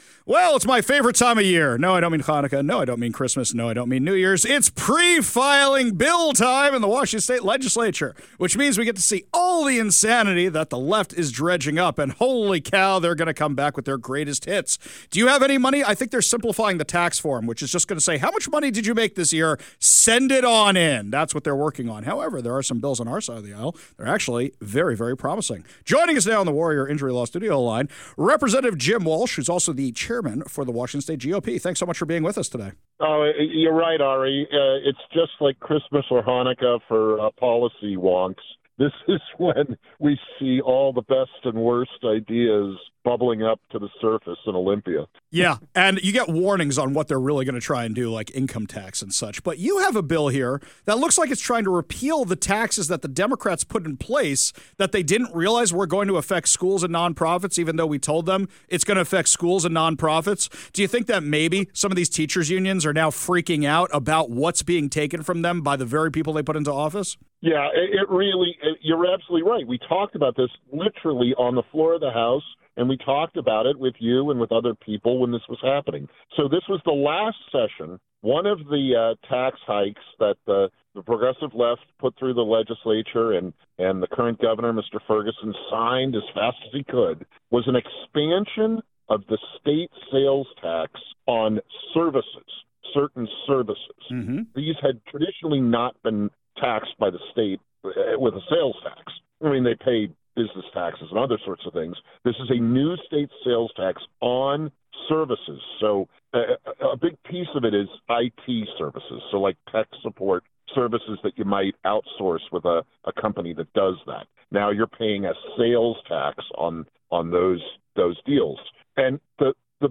A candid, no-spin conversation about taxes, budget messes, and how Republicans plan to seize the agenda in a legislature dominated by the left.